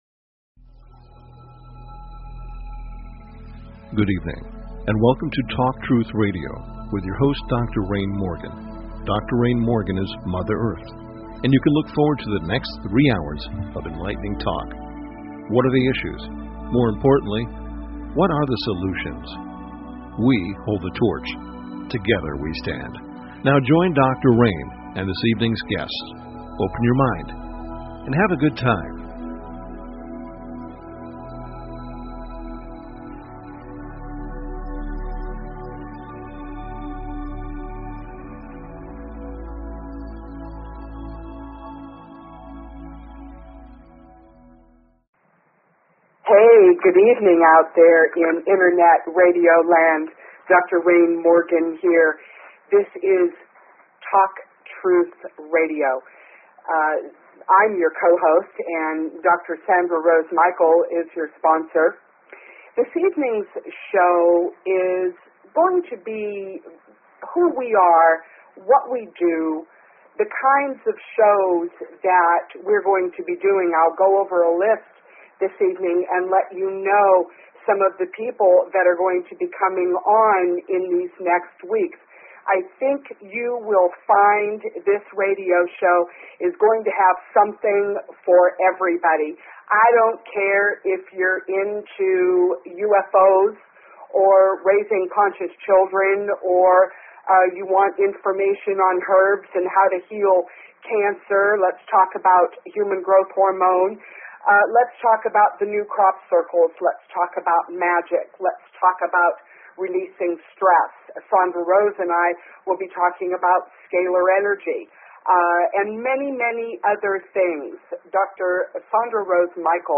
Talk Show Episode, Audio Podcast, Talk_Truth_Radio and Courtesy of BBS Radio on , show guests , about , categorized as